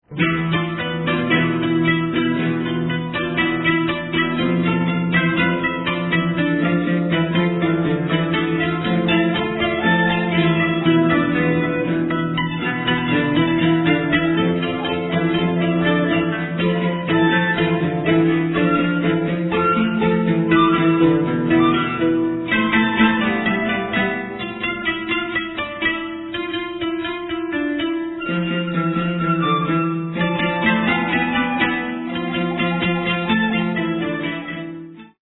traditional Japanese music
Recorded in Japan